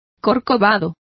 Complete with pronunciation of the translation of humpbacked.